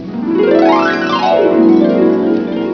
Harp.wav